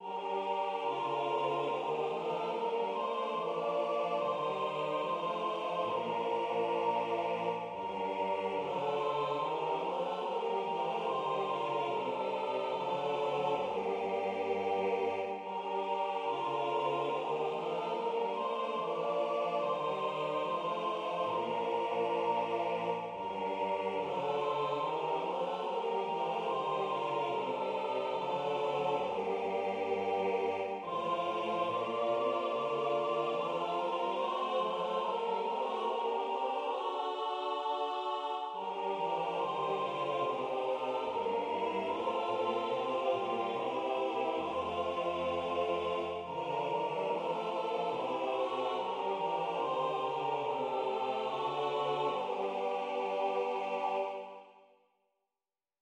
7 Wir wachen oder schlafen ein Gigas Chorale SATB Co 2Ob 2Vl Va G minor common time
The cantata ends with a four-part setting of the chorale melody, "Wir wachen oder schlafen ein" (Whether we wake or fall asleep),[1][13] expressing "confidence in God".[3]